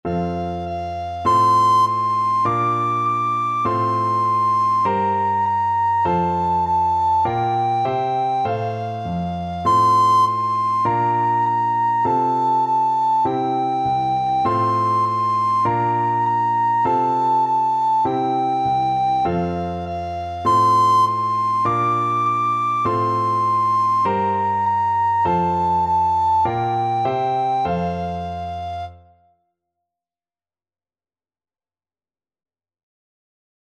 Recorder
F major (Sounding Pitch) (View more F major Music for Recorder )
Moderato
4/4 (View more 4/4 Music)
Traditional (View more Traditional Recorder Music)